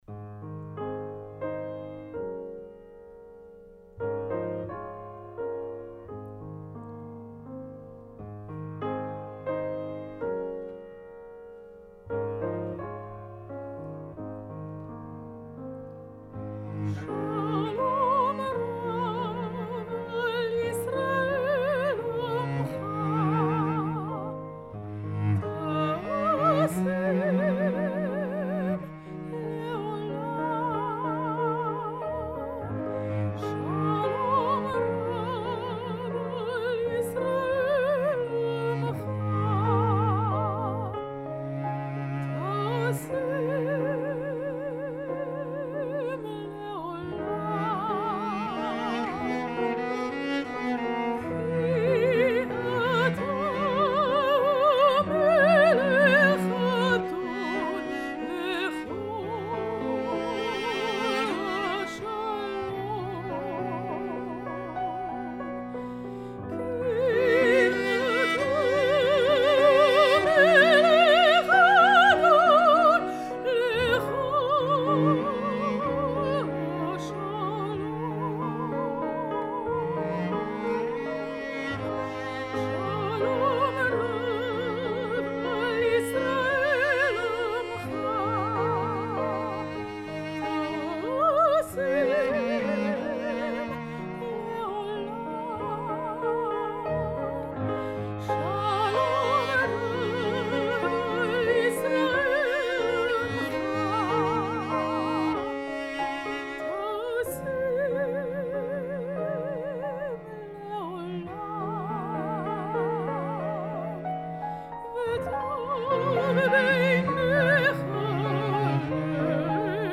Soprano
Cello
Piano